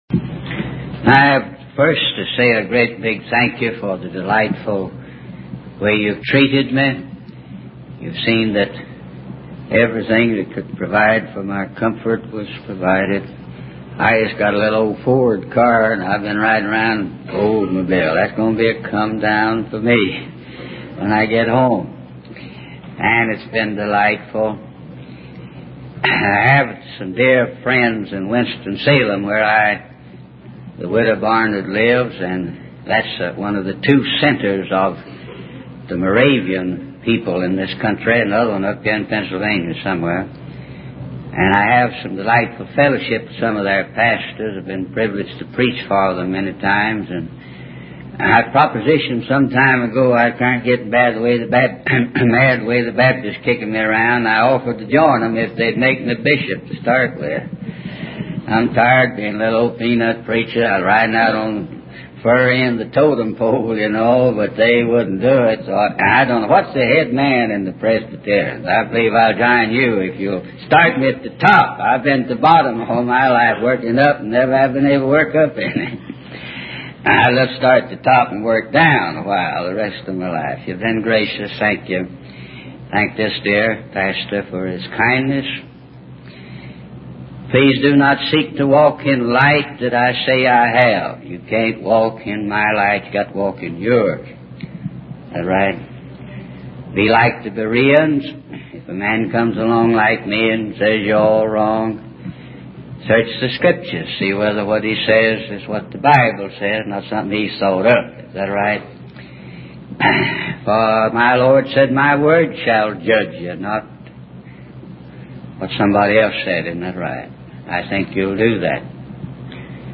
The preacher encourages the audience to bow down to Jesus and sings a chorus as a farewell. He also discusses the importance of taking the battle to the enemy and not just defending ourselves.